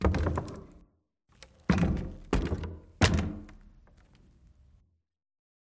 amb_fs_stumble_wood_10.mp3